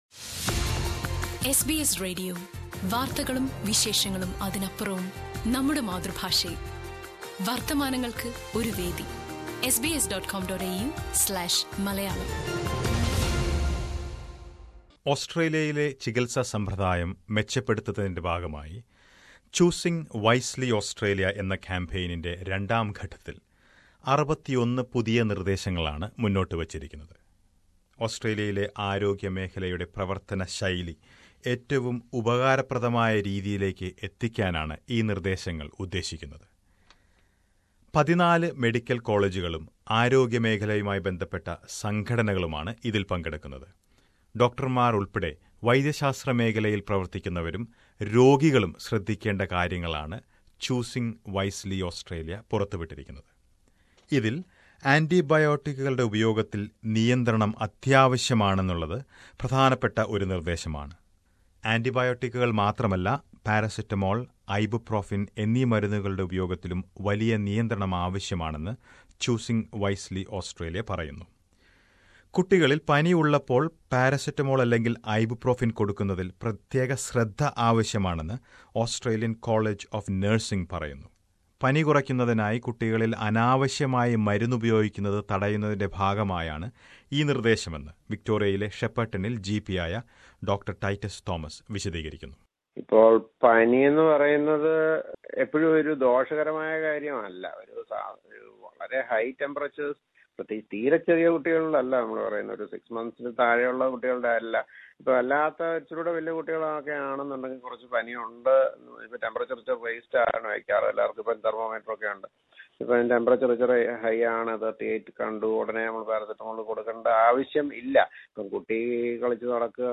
ഇതേക്കുറിച്ച് ഒരു റിപ്പോര്‍ട്ട് കേള്‍ക്കാം മുകളിലെ പ്ലേയറിൽ നിന്ന്...